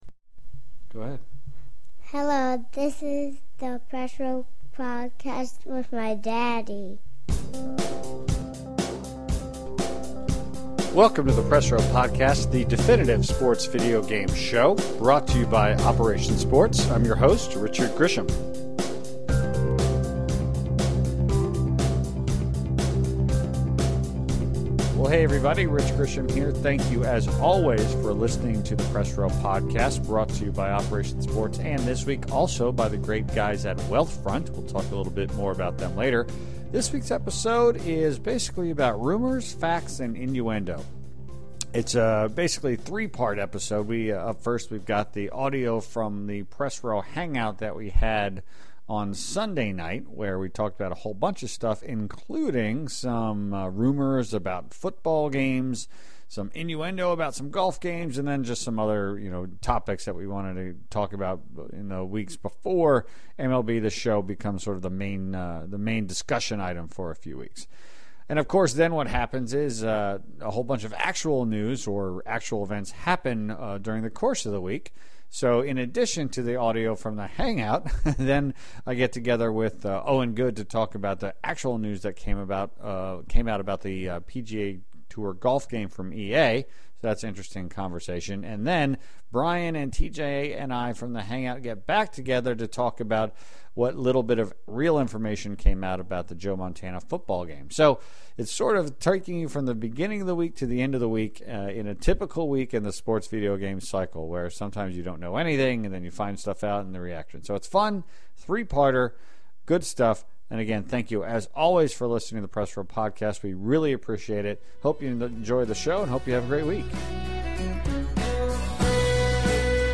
a one-on-one conversation